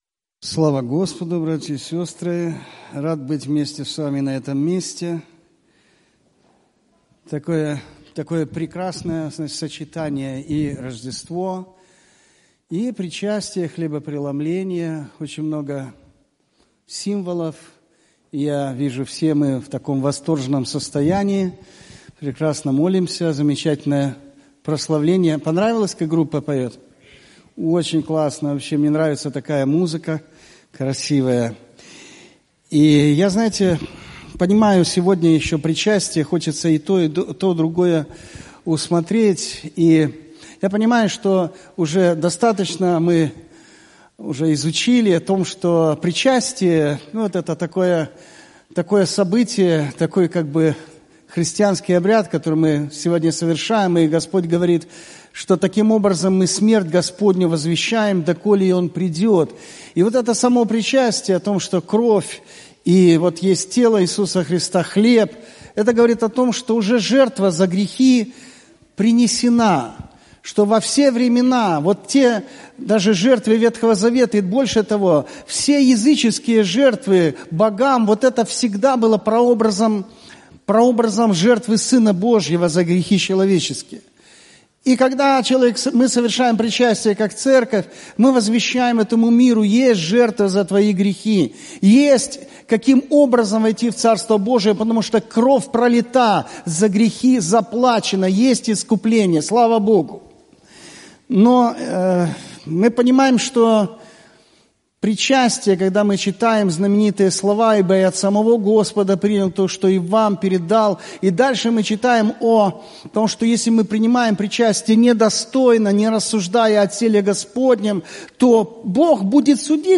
Пропаведзі